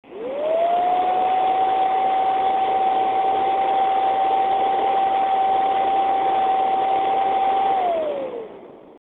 (high) FrostyTech Acoustic Sampling Chamber